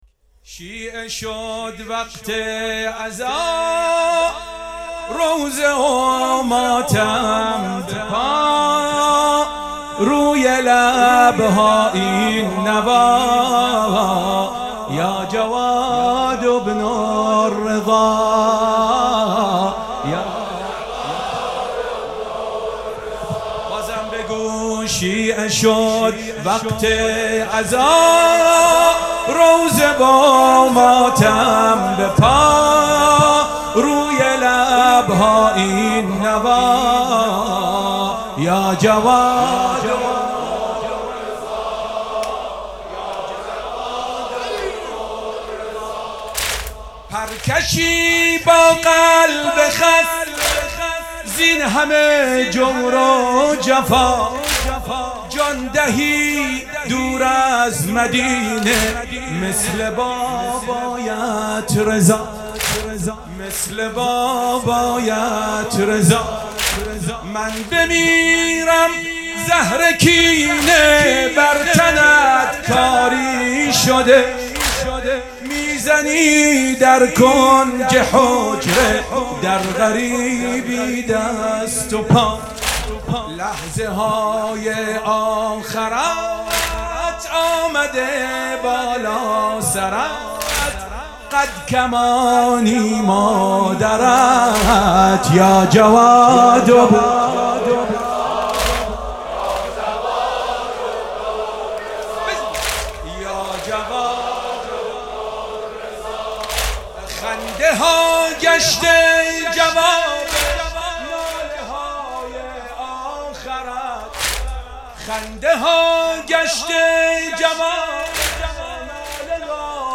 مداحی به سبک واحد اجرا شده است.